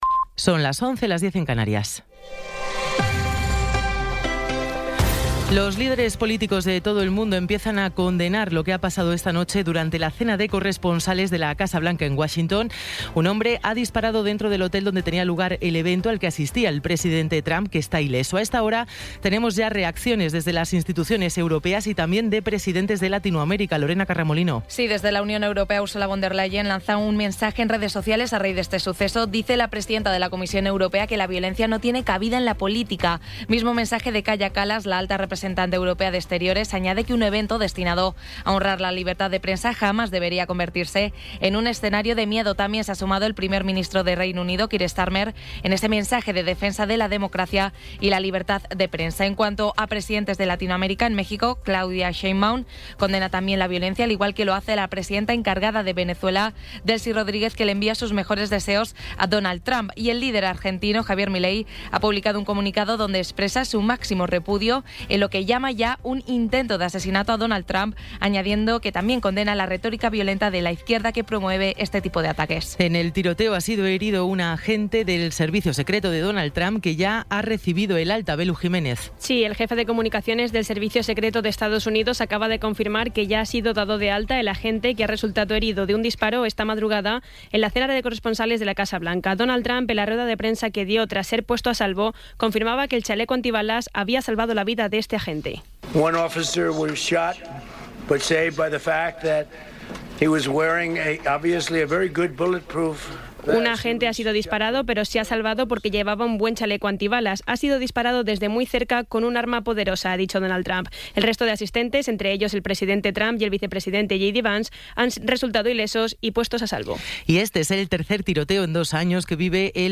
Resumen informativo con las noticias más destacadas del 26 de abril de 2026 a las once de la mañana.